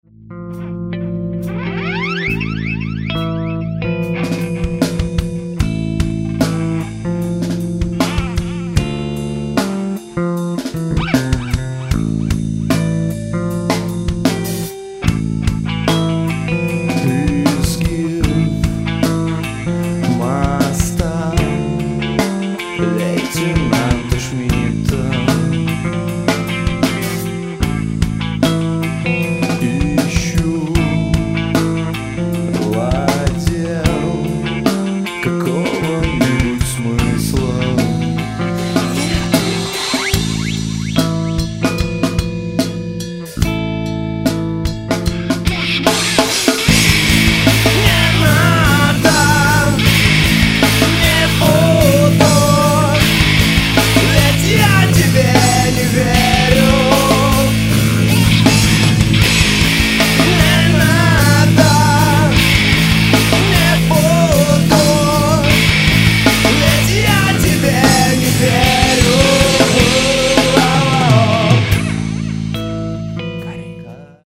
Тяжело, мрачно и красиво.
Давящая, тяжёлая, депрессивная атмосфера.